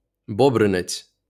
Bobrynets (Ukrainian: Бобринець, IPA: [ˈbɔbrɪnetsʲ]